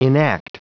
Prononciation du mot enact en anglais (fichier audio)